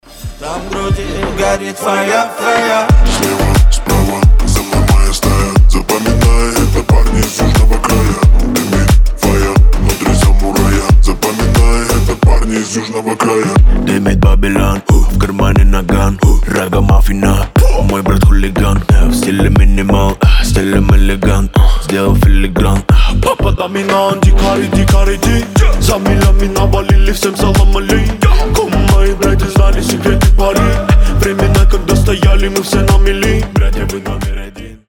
пацанские
басы